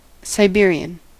Ääntäminen
Ääntäminen US Haettu sana löytyi näillä lähdekielillä: englanti Käännös Substantiivit 1.